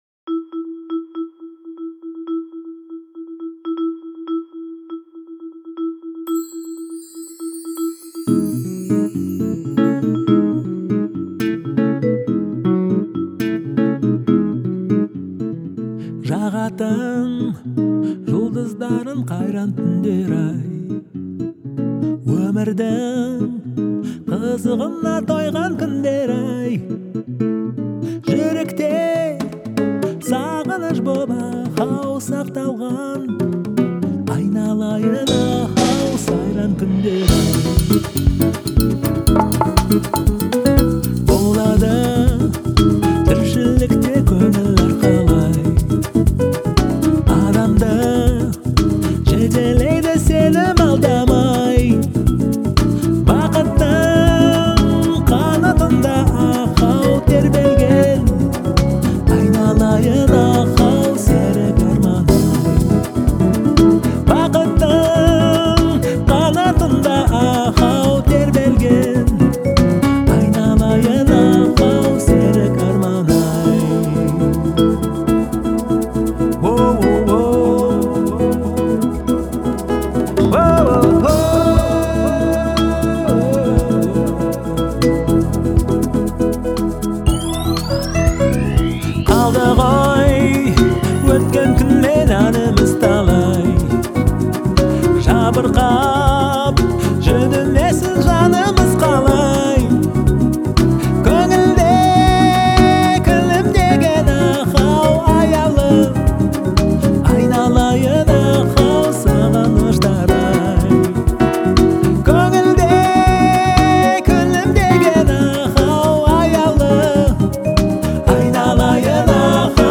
это яркий пример казахского поп-рока